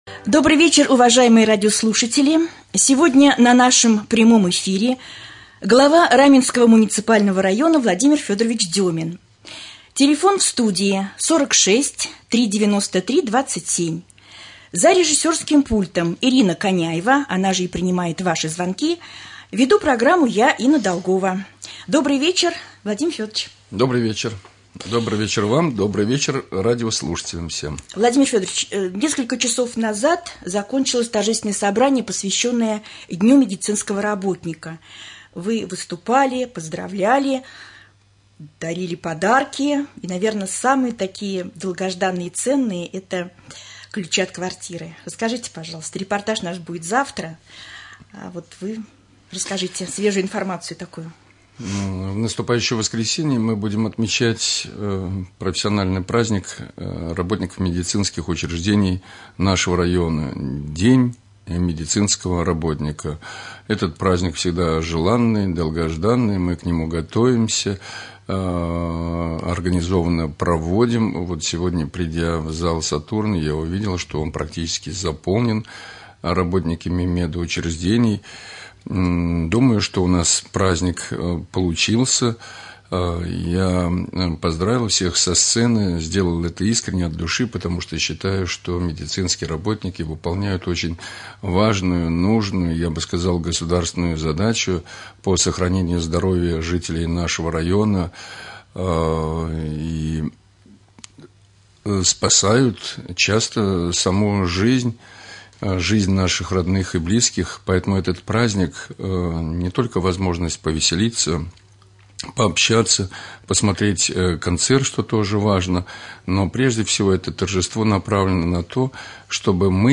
Прямой эфир. Гость студии Глава Раменского муниципального района Владимир Федорович Демин.